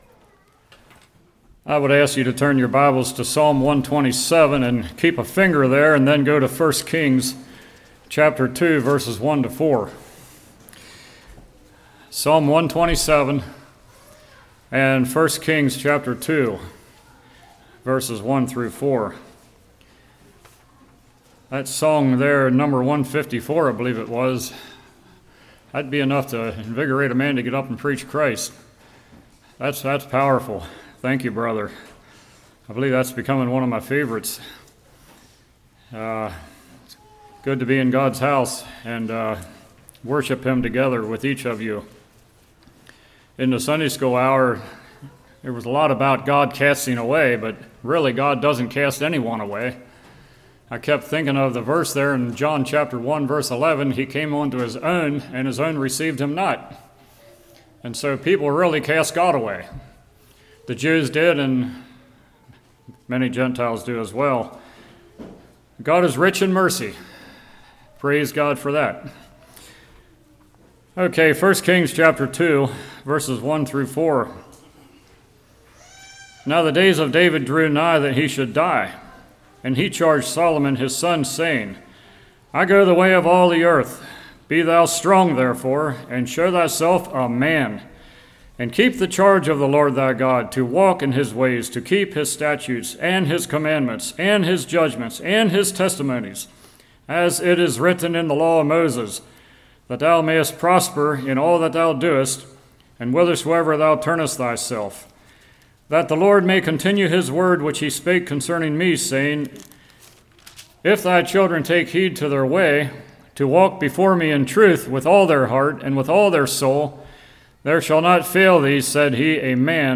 Psalms 127:1-5 Service Type: Morning Love the Lord thy God.